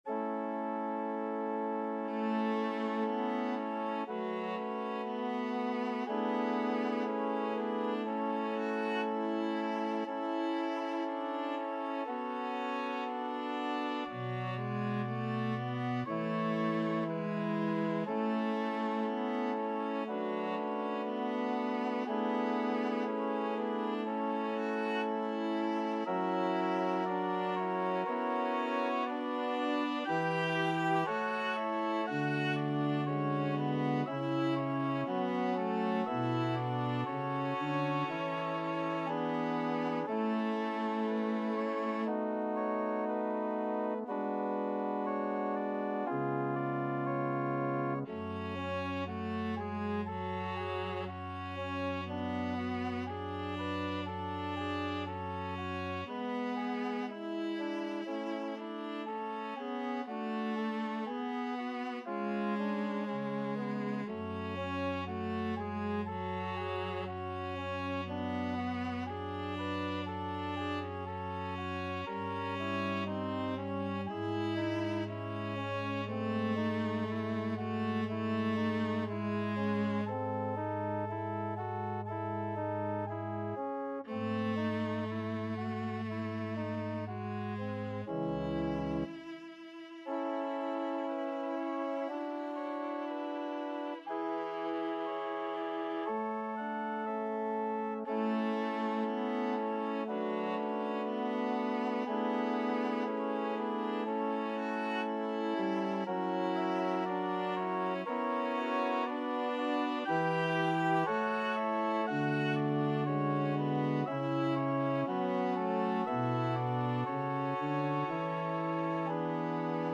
Viola
A major (Sounding Pitch) (View more A major Music for Viola )
2/4 (View more 2/4 Music)
~ = 100 Andantino sempre legato =60 (View more music marked Andantino)
Classical (View more Classical Viola Music)